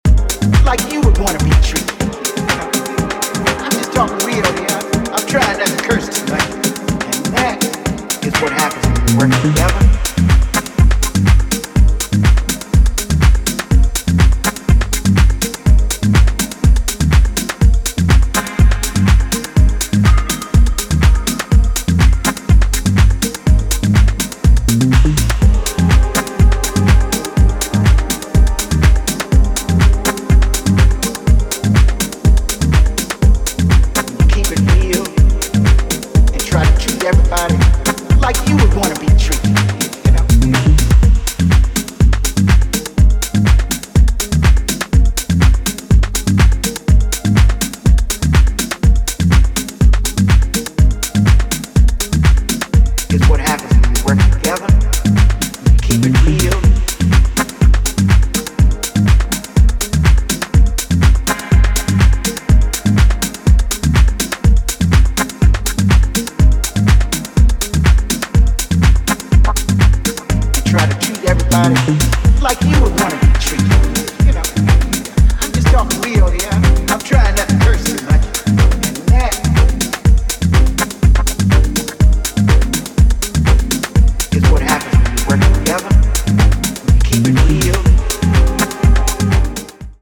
メランコリックなダブハウス
よりソリッドに洗練されたそちらも良いのでお聴き逃しなく。